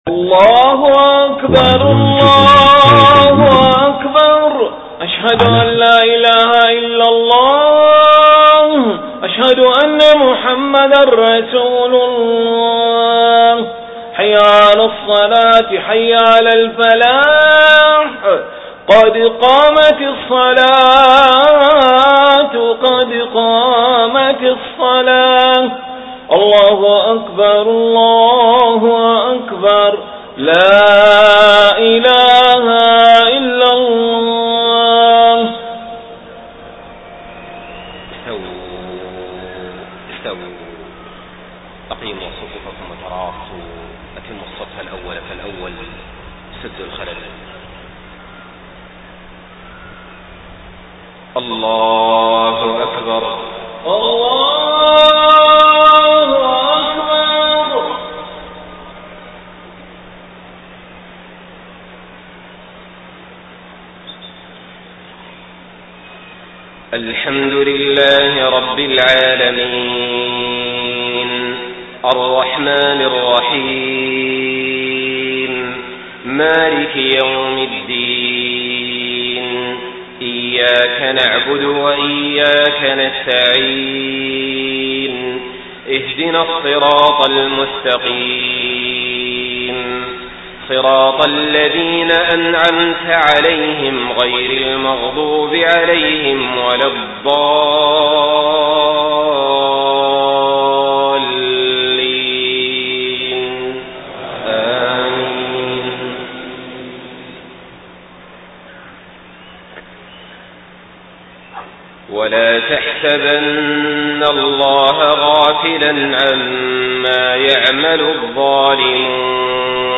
صلاة العشاء 30 صفر 1431هـ خواتيم سورة إبراهيم 42-52 > 1431 🕋 > الفروض - تلاوات الحرمين